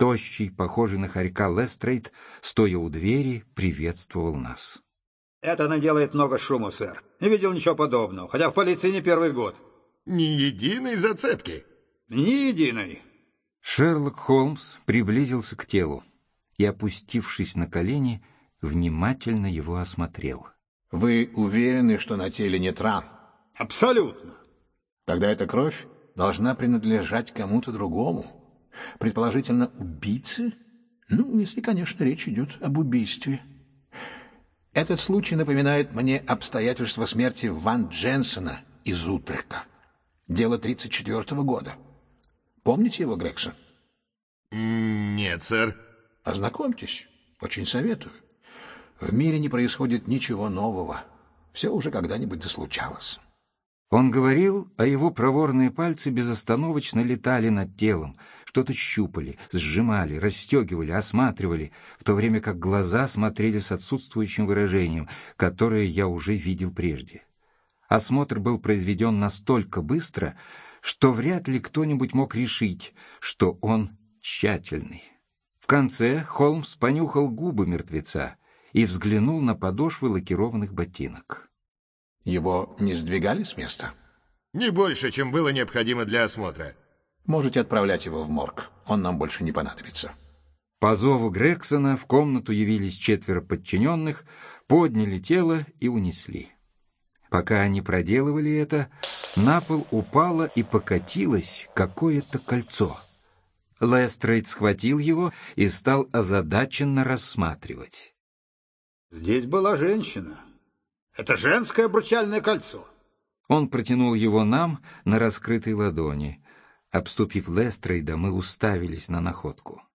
Аудиокнига Этюд в багровых тонах (спектакль) | Библиотека аудиокниг
Aудиокнига Этюд в багровых тонах (спектакль) Автор Артур Конан Дойл Читает аудиокнигу Виктор Раков.